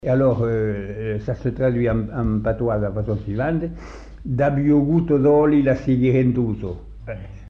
Genre : forme brève
Type de voix : voix d'homme
Production du son : récité
Classification : proverbe-dicton